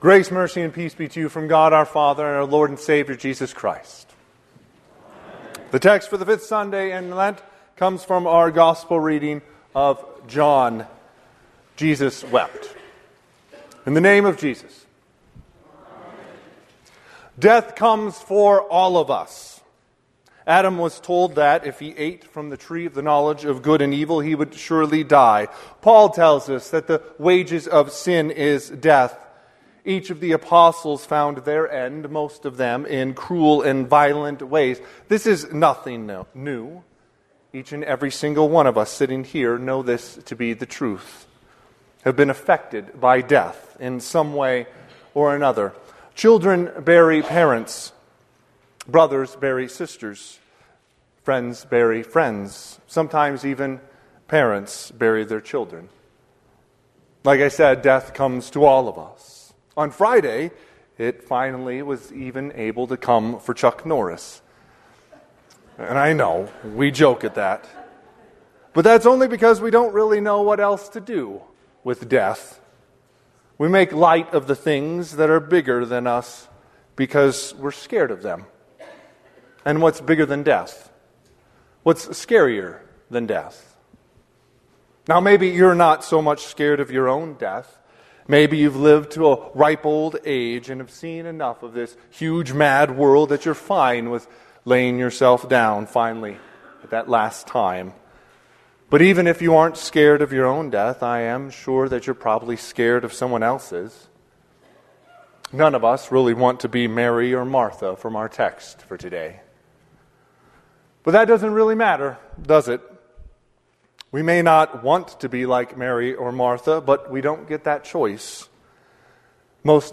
Sermon - 3/22/2026 - Wheat Ridge Evangelical Lutheran Church, Wheat Ridge, Colorado
Fifth Sunday in Lent